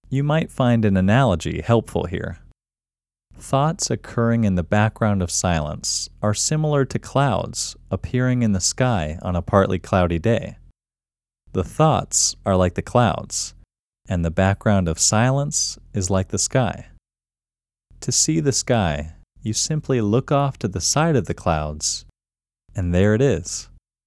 QUIETNESS Male English 7
The-Quietness-Technique-Male-English-7.mp3